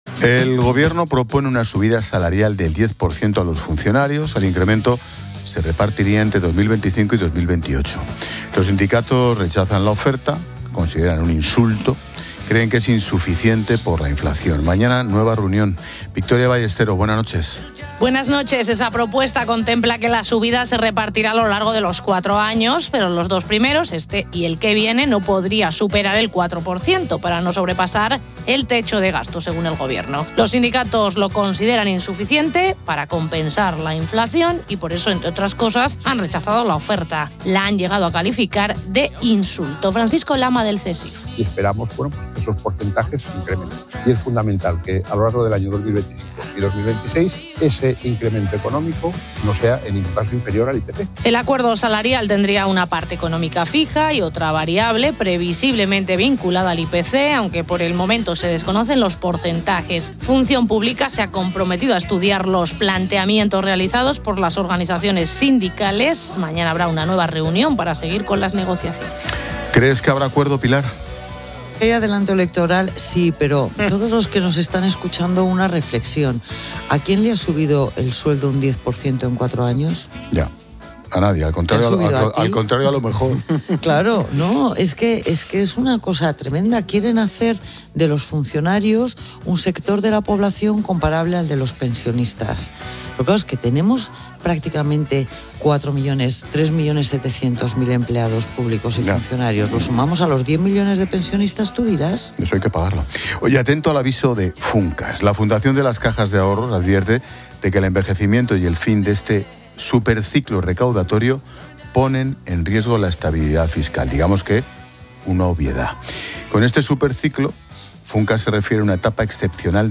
Ángel Expósito analiza con Pilar García de la Granja, experta económica y directora de Mediodía COPE, la medida del Gobierno que propone una subida salarial del 10 por 100 a los funcionarios